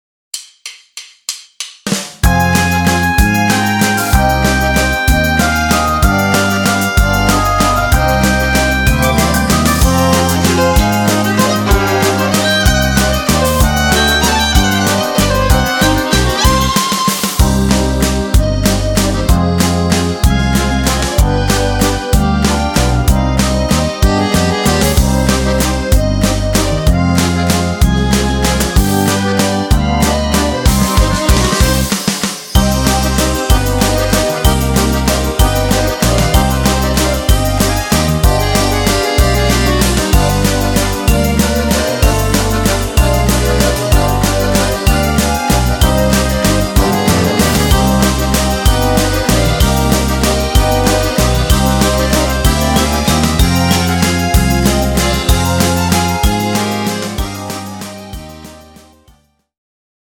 Piękna ludowa piosenka w nowej odsłonie aranżacyjnej